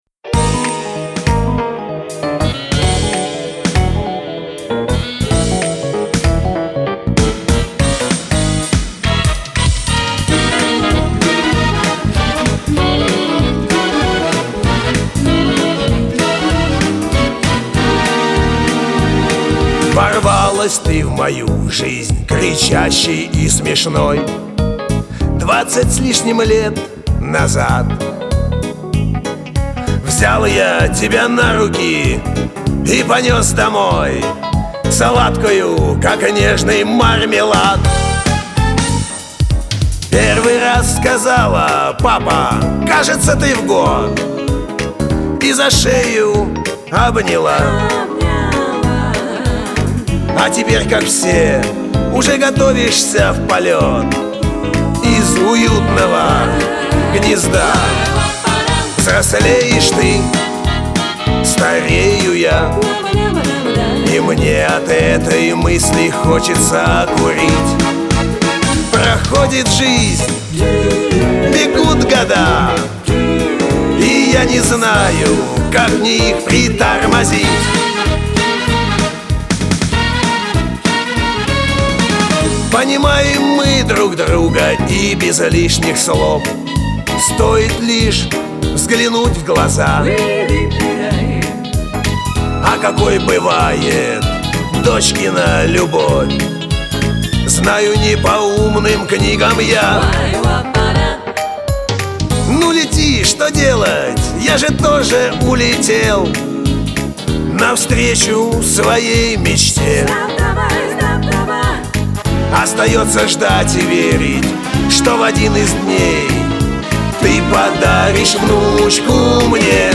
весёлое настроение, задор, кураж